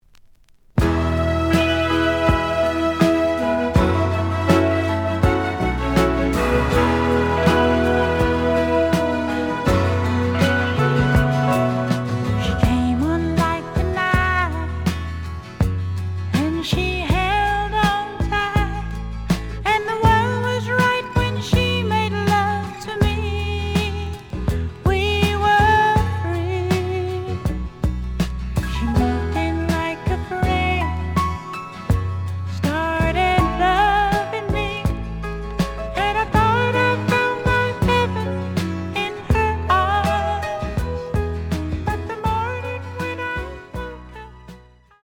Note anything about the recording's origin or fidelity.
The audio sample is recorded from the actual item. ●Format: 7 inch